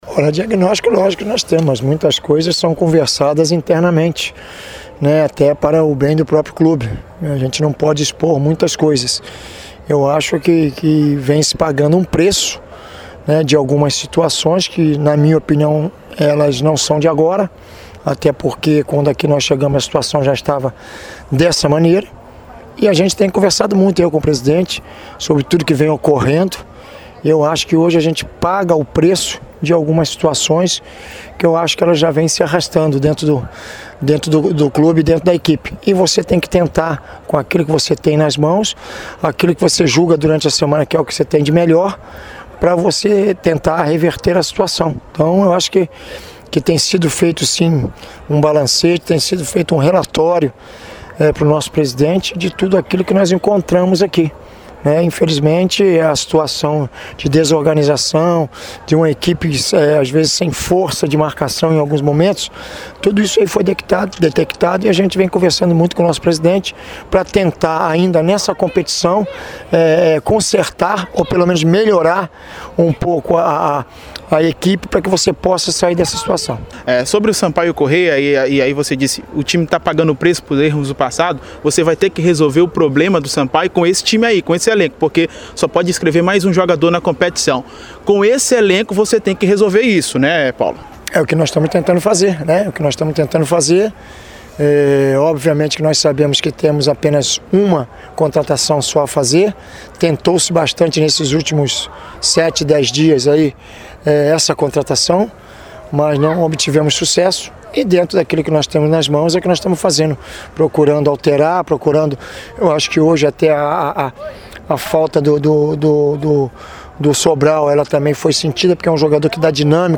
O treinador fala também sobre o duelo contra Vila Nova, que acontece nesta terça-feira. Confira a entrevista em áudio abaixo.